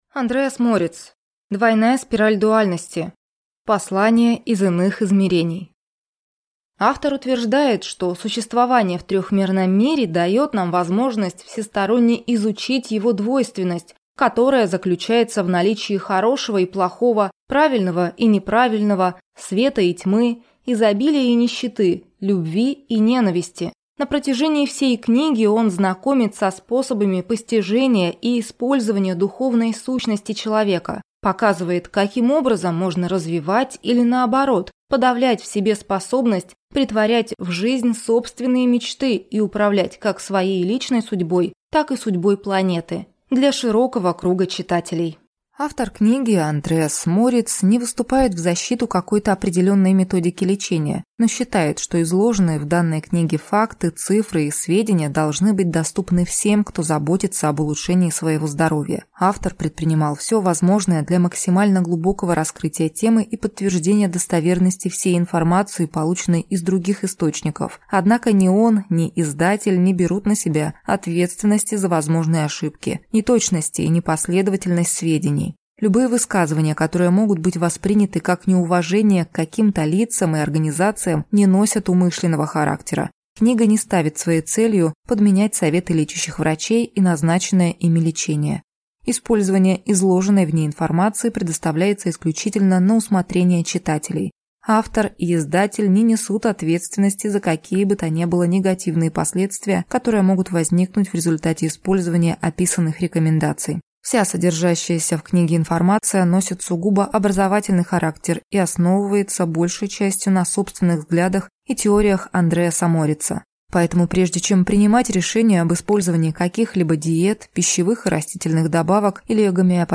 Аудиокнига Двойная спираль дуальности. Послания из иных измерений | Библиотека аудиокниг